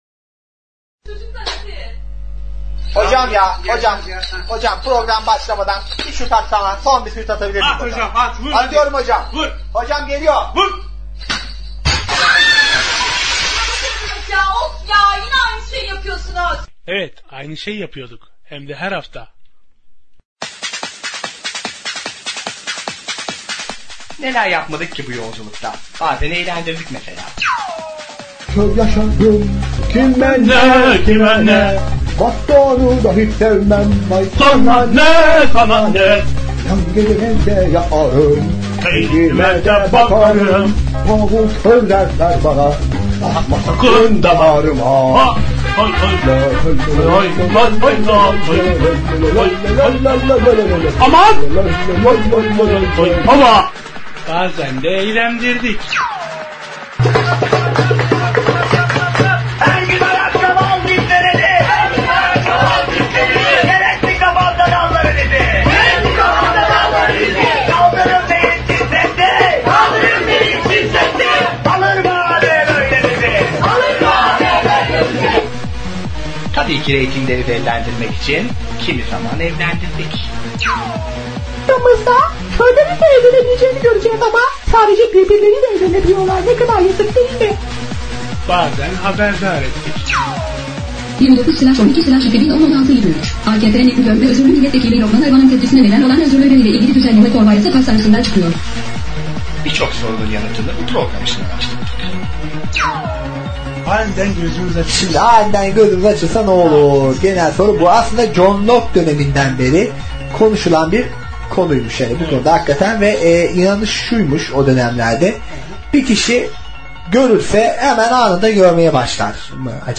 Engelsiz Erişimli Saatler her Çarşamba akşamı saat 21:00-23:00 saatleri arasında Eğitimde Görme Engelliler Radyosunda.